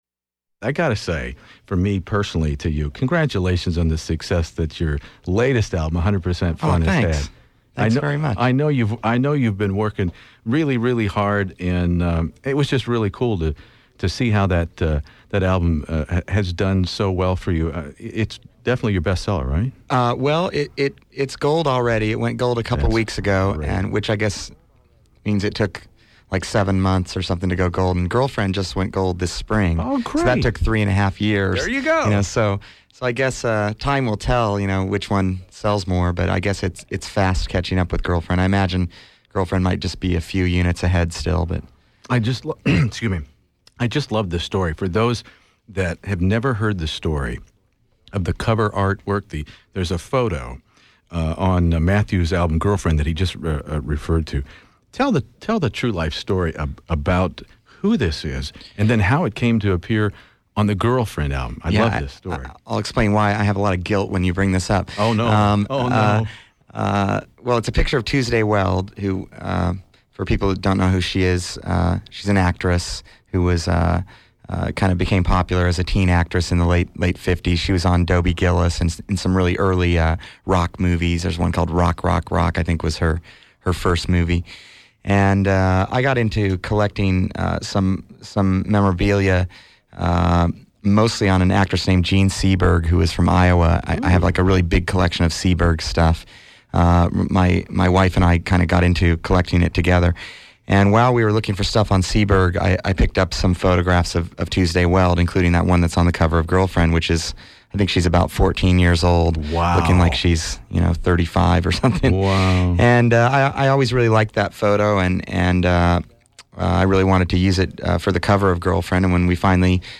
Here’s my October 1995 rock music interview with Matthew Sweet discussing Japanese animation; making videos for MTV in a pre-YouTube world; alternative godfathers Tom Verlaine and Richard Lloyd’s band Television and Marquee Moon ( the most forgotten of the CBGB-era pioneers that finally got some recognition via the recent movie); guitarists Richard Hell and Robert Quine; car customizer George Barris; 1970 Dodge Challengers painted “Purple Passion”; 1960s teen actress Tuesday Weld; Matthew’s follow-ups Altered Beast from July 1993, and 100% Fun…you know, all the basics.